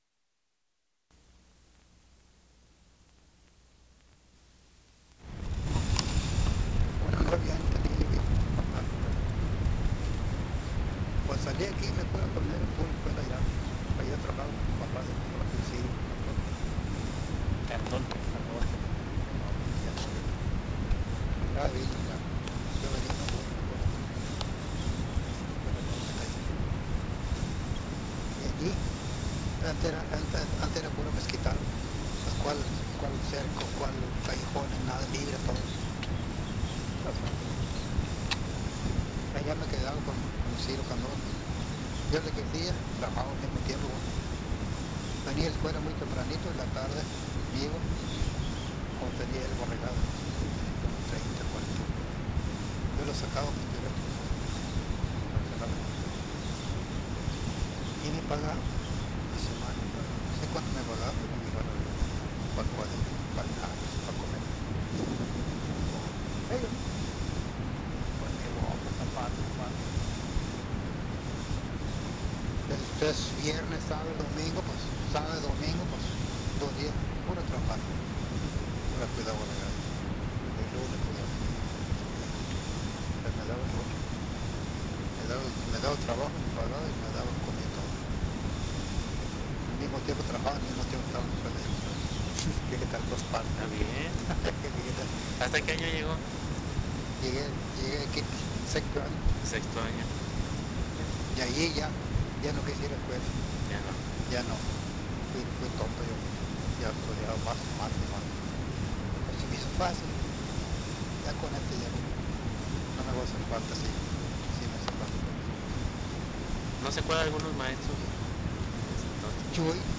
Entrevistado
Entrevistador